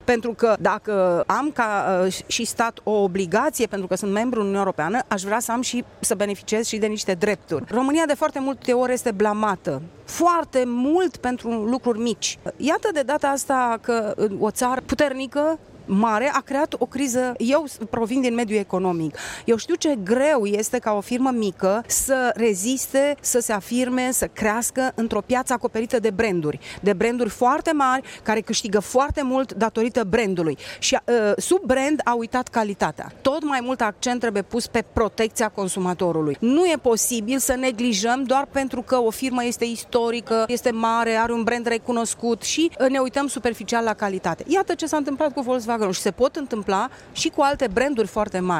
Pe lângă cele 8 milioane de posesori de autoturisme afectate sunt şi multe întreprinderi mici şi mijlocii, furnizori din lanţul de producţie, mulţi din România, mai spune eurodeputatul Maria Grapini:
Declaraţii pentru Radio România